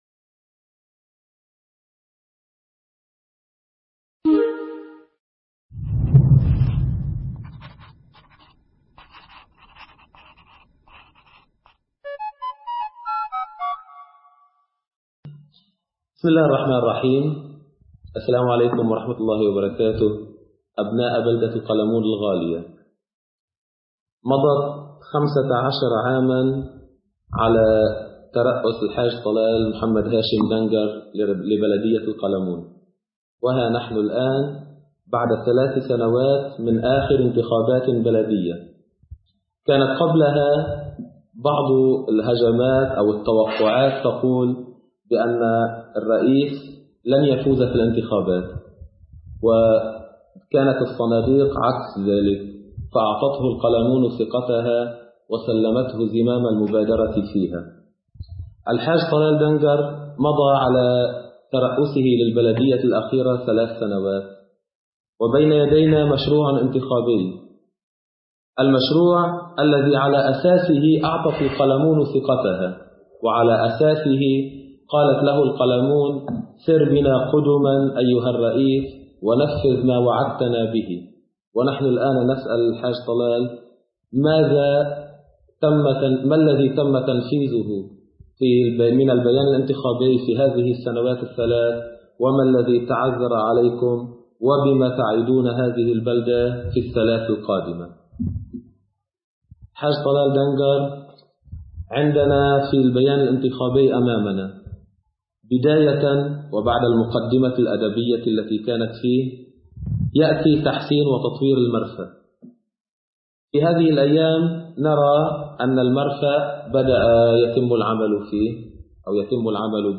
[تحديث] لقاء الموقع مع رئيس البلدية الحاج طلال دنكر
نضع بين يديكم اللقاء المطول الذي أجراه موقع بلدة القلمون في لبنان مع رئيس بلدية القلمون للمرة الثالثة